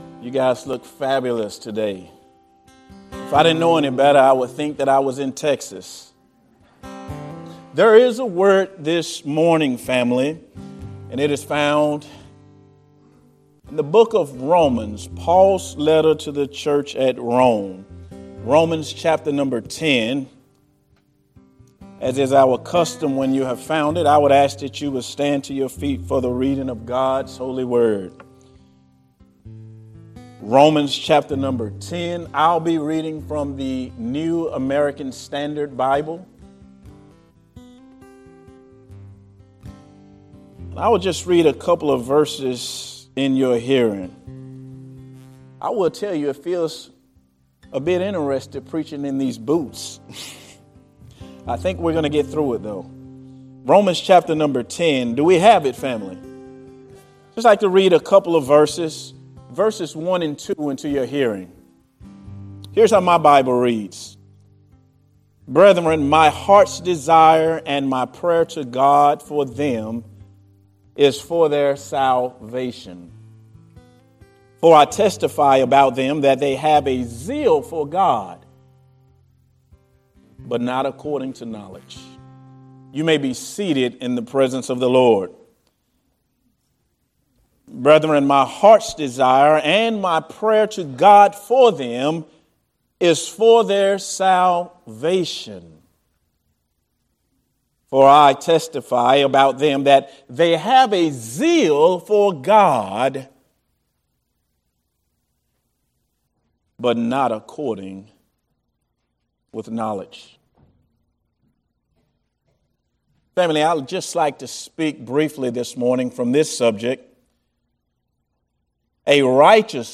Sunday Sermons from North Oaks Baptist Church in Spring, TX